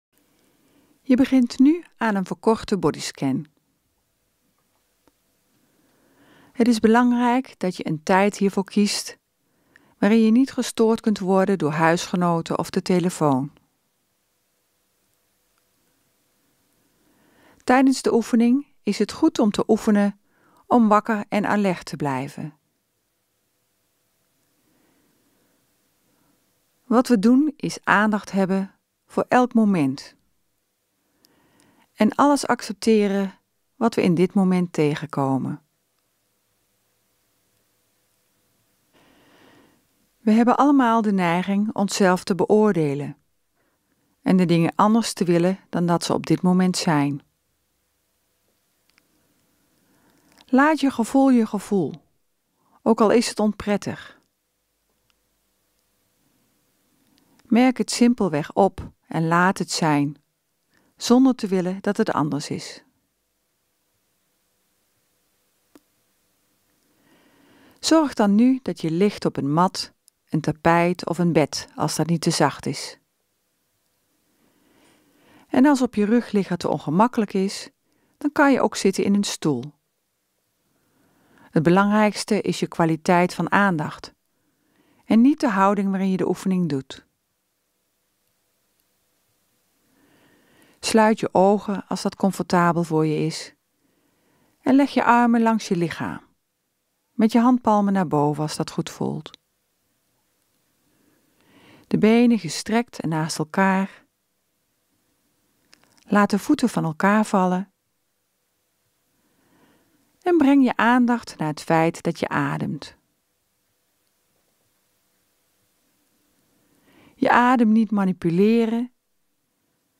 Bodyscan-korter.mp3